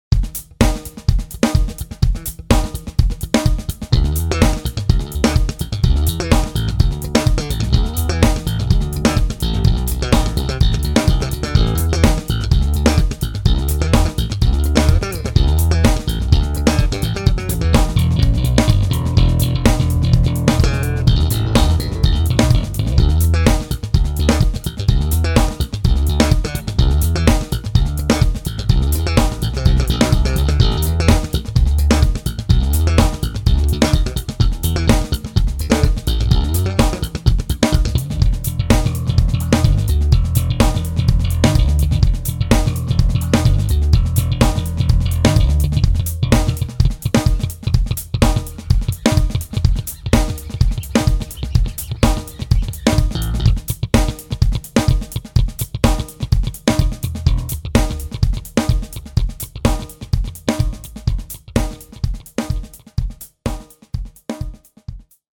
Musicman Stingray Electric Bass - ������
Musicman Stingray EX Electric Bass Guitar Japan Made - ����������� ������� ��������� - �� �������� � ������������� ��� ����.��...